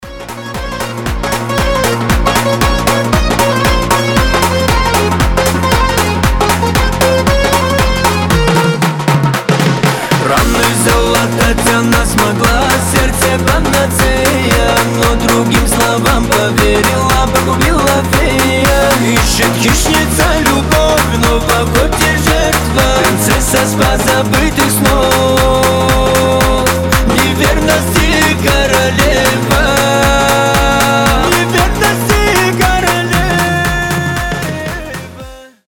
на припеве быстрые страдания